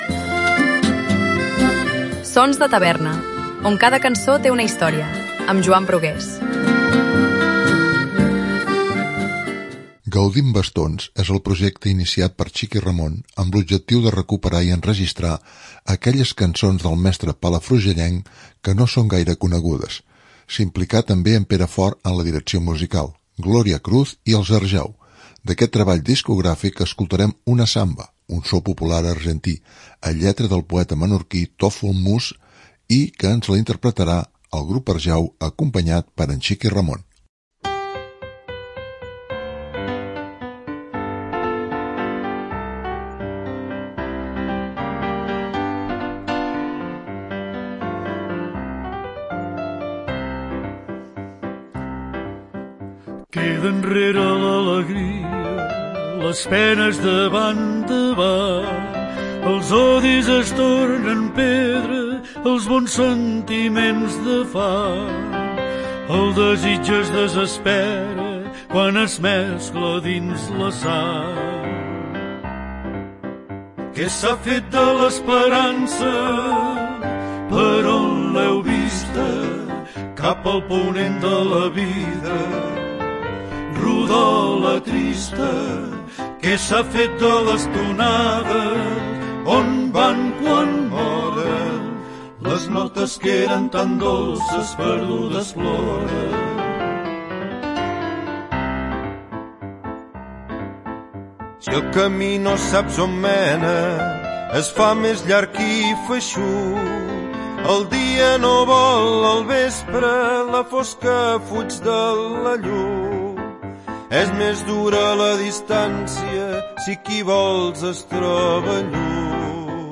una zamba -so popular argentí-